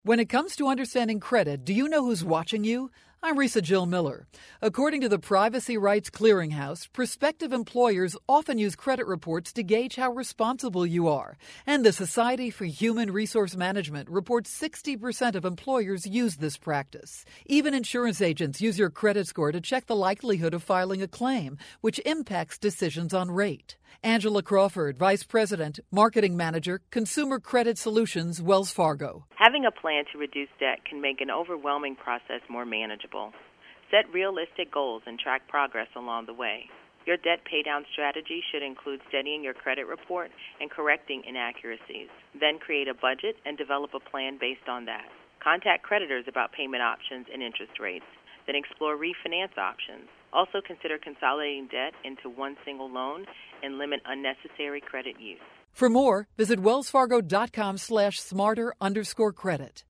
October 19, 2012Posted in: Audio News Release